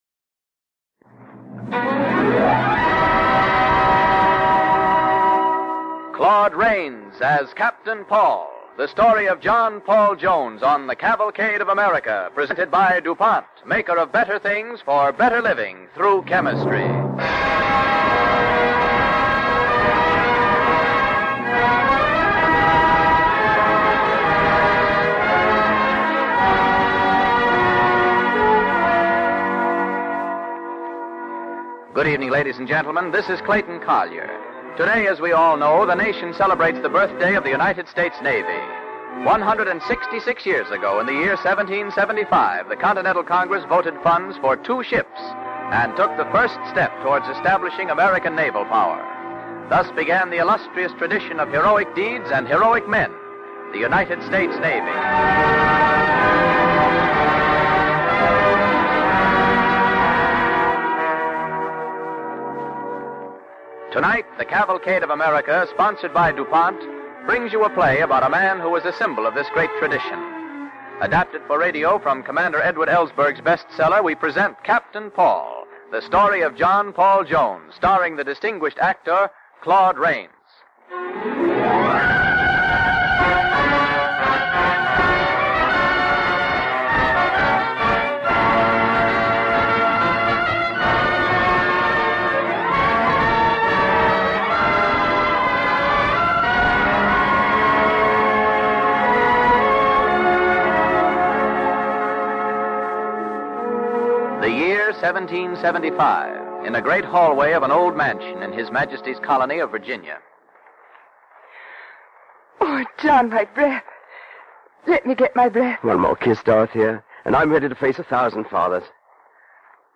Captain Paul, starring Claude Rains
Cavalcade of America Radio Program